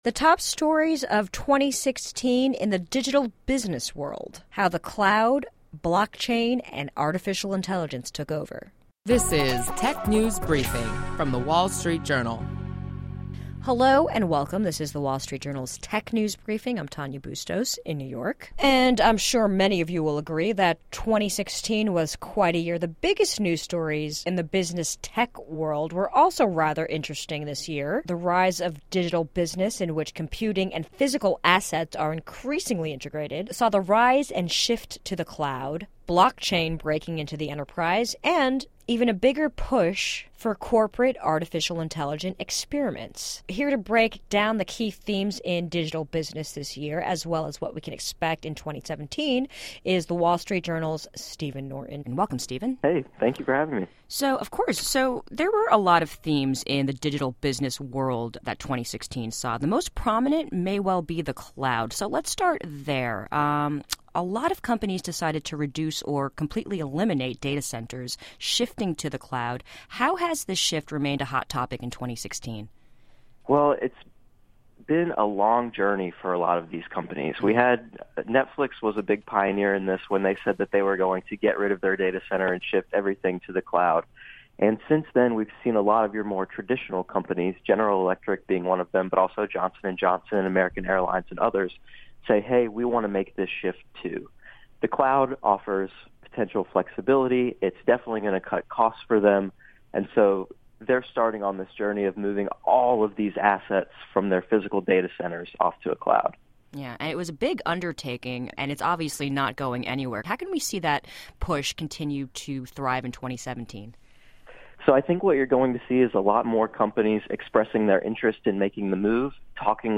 Stay informed on the latest trends with daily insights on what’s hot and happening in the world of technology. Listen to our reporters discuss notable company news, new tech gadgets, personal technology updates, app features, start-up highlights and more.